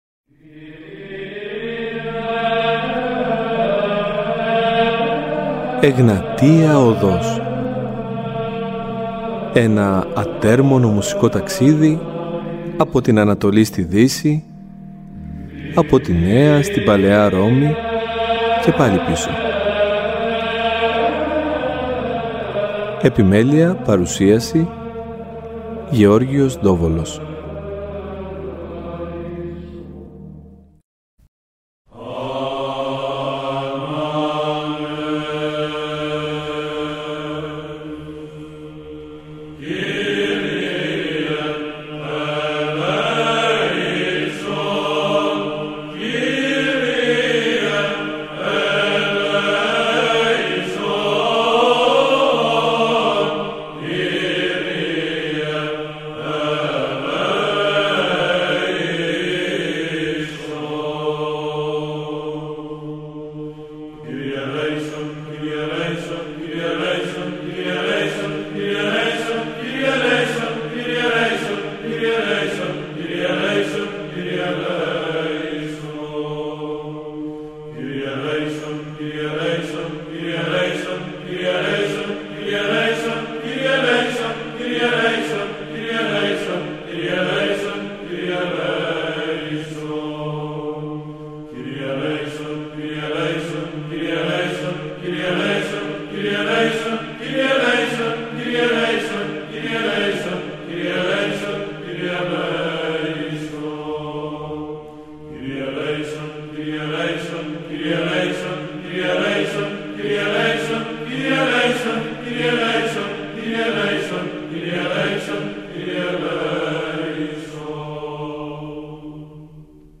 Συζητουν επίσης για το ρόλο της ψαλτικής τέχνης στο σήμερα και για την απήχηση της στο σύγχρονο άνθρωπο. Παράλληλα θα ακουστούν επίκαιροι ύμνοι από την υμνολογία των ημερών.